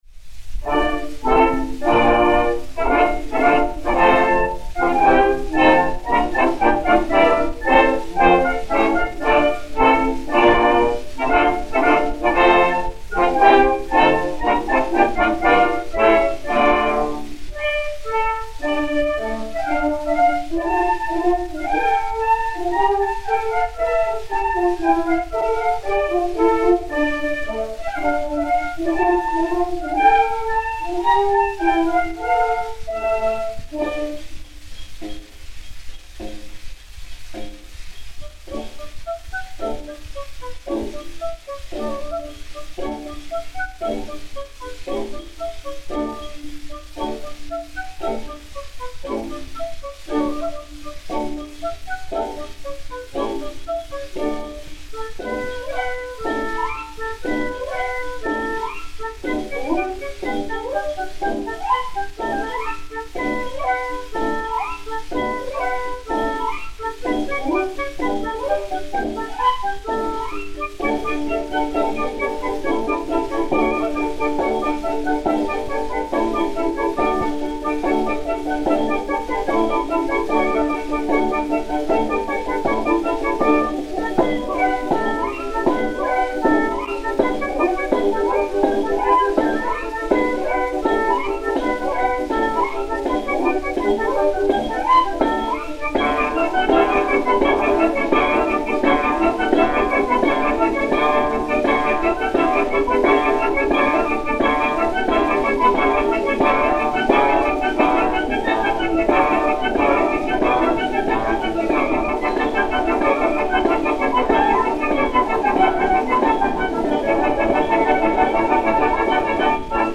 Orchestre Pathé Frères